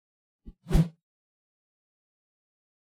meleeattack-swoosh-heavy-group06-01.ogg